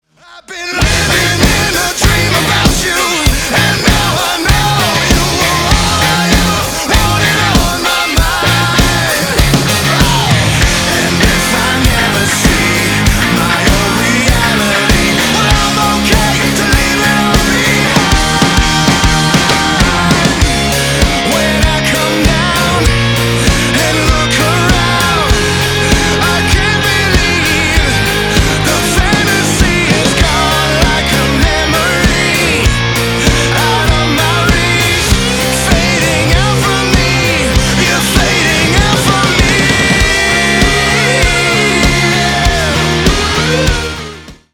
• Качество: 320, Stereo
позитивные
мужской голос
громкие
Драйвовые
электрогитара
Alternative Rock
Hard rock
indie rock